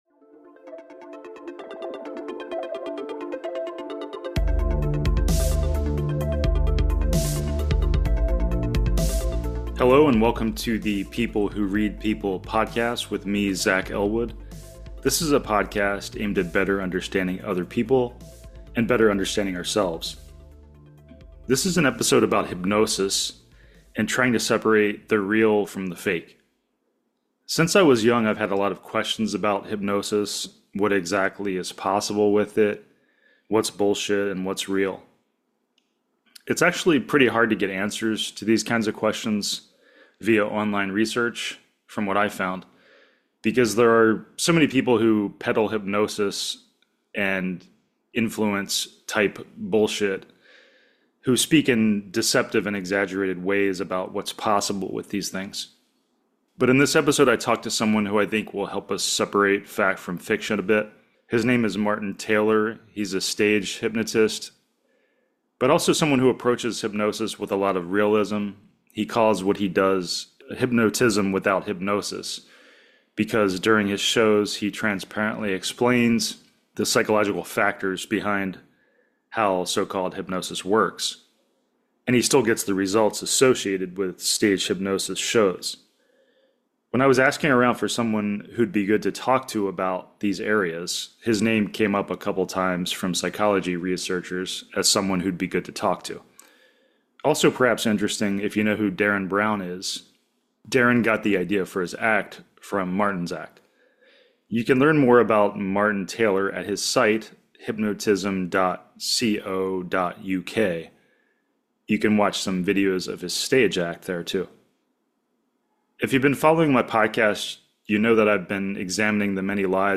A talk about hypnosis and mind control